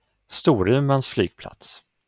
Аэропо́рт Сторуман (швед. Storumans flygplats, произношение: [ˈstuːrʉˌmanːs ˈflyːɡplats]
произношение (инф.))